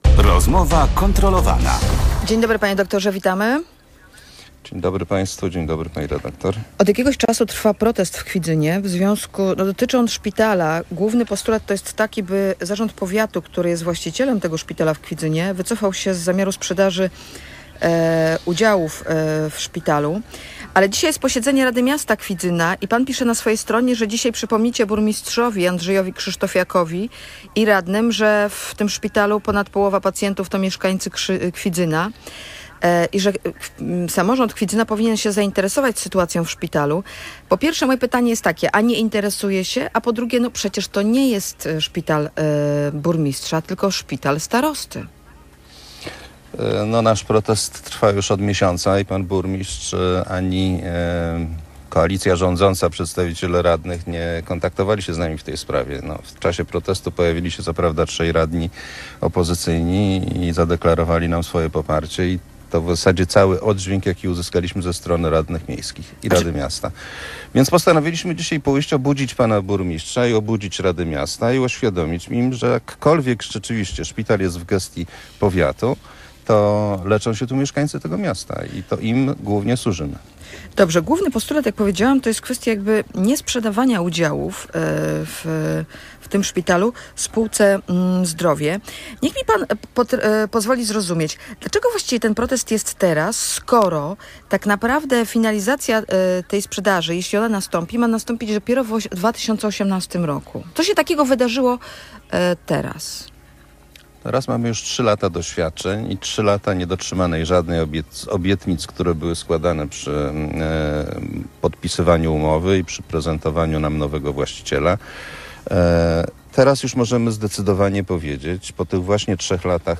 mówił w Radiu Gdańsk